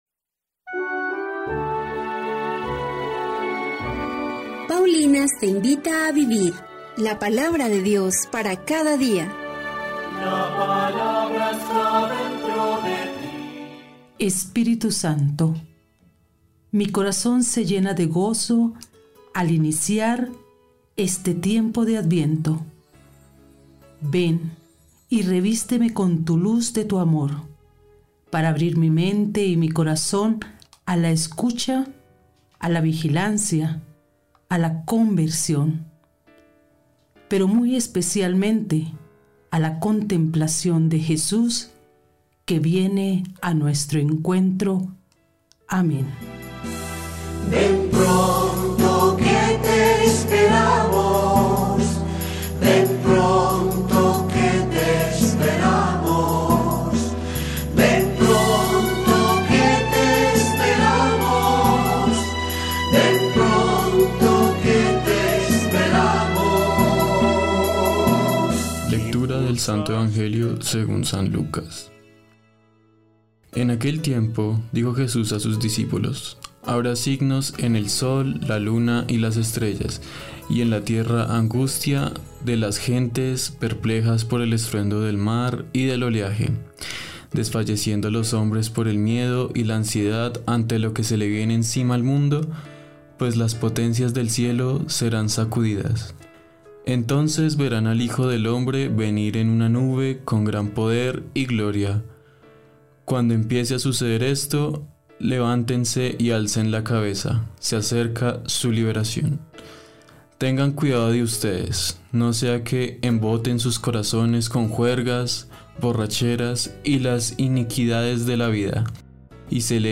Liturgia-1-de-Diciembre.mp3